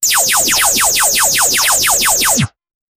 laser-gun-shoot-m5x4ecez.wav